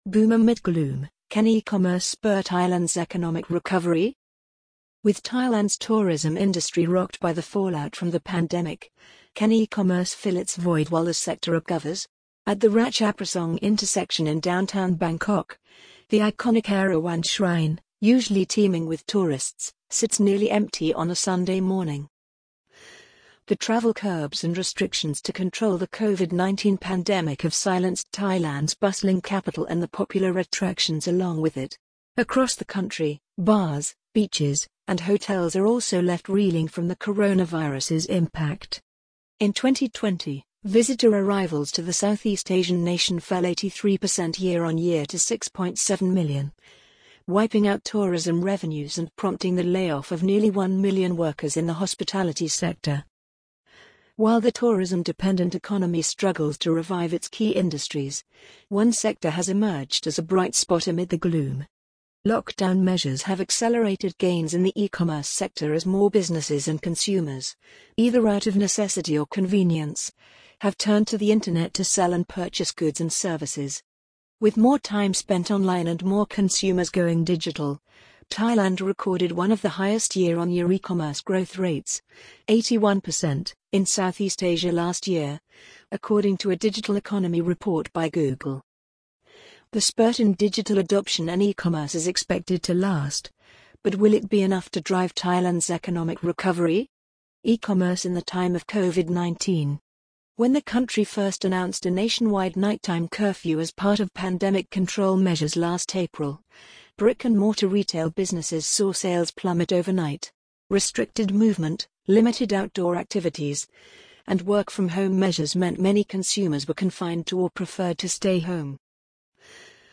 amazon_polly_12411.mp3